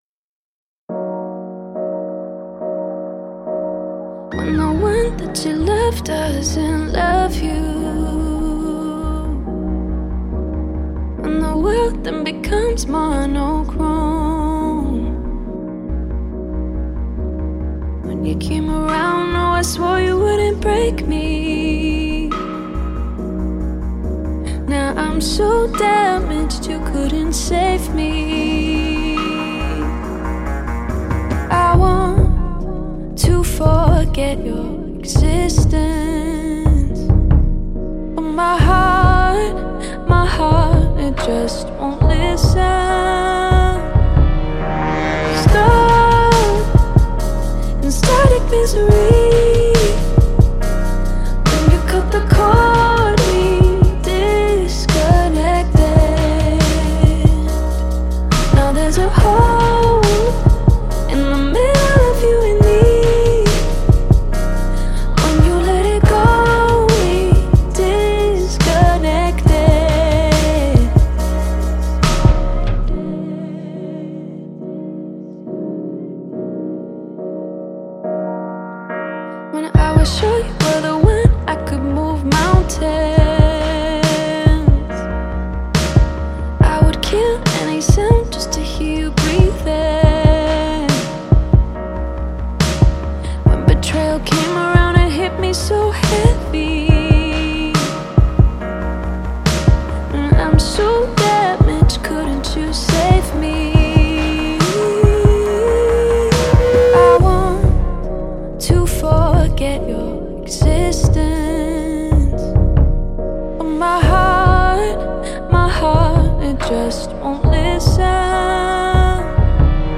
# R&B # Pop # Soul